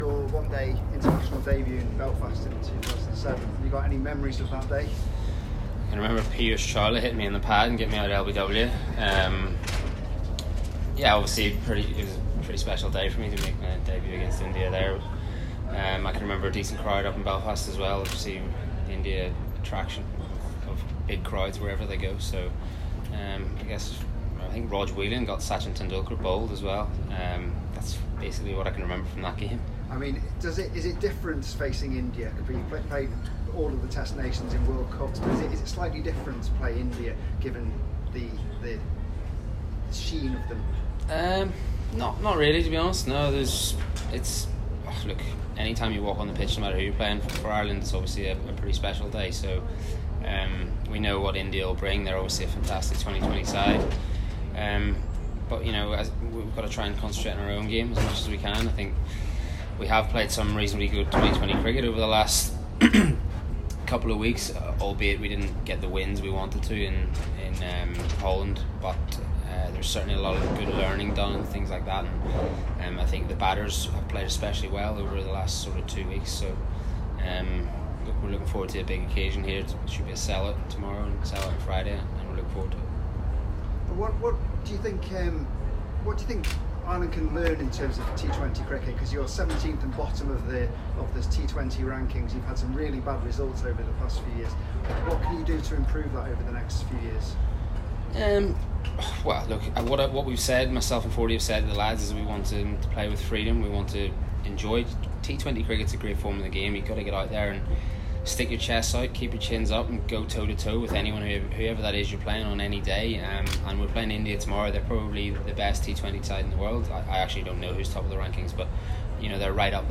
Ireland v India T20Is - Gary Wilson Ireland Captain fields questions from the Media
DUBLIN – Ireland’s T20I Captain Gary Wilson spoke to the media this afternoon at Malahide, ahead of the first match against India tomorrow.